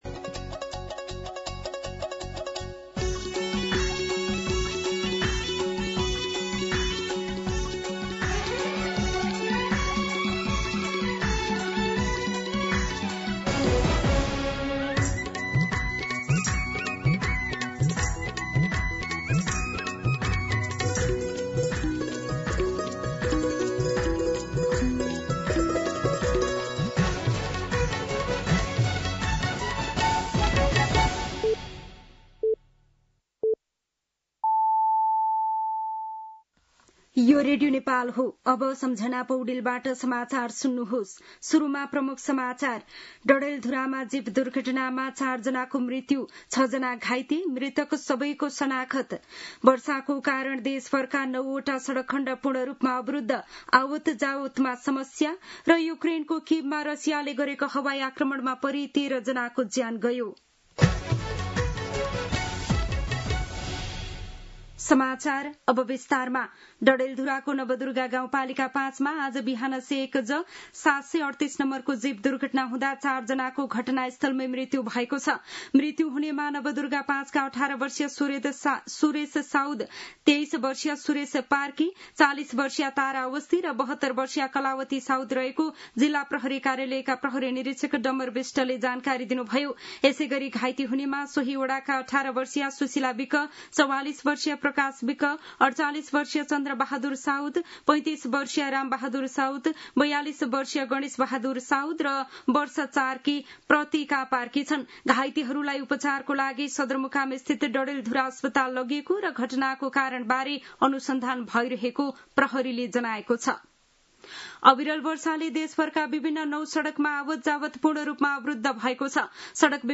दिउँसो ३ बजेको नेपाली समाचार : १२ भदौ , २०८२
3pm-News-05-12.mp3